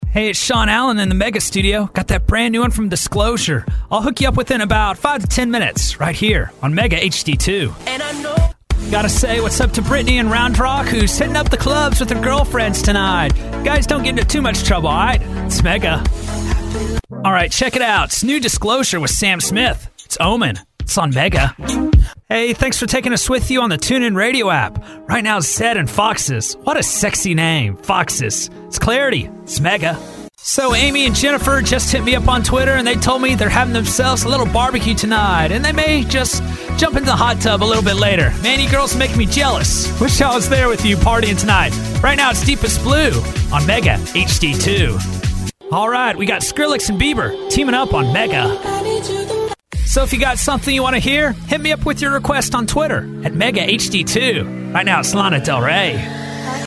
On-Air Demos: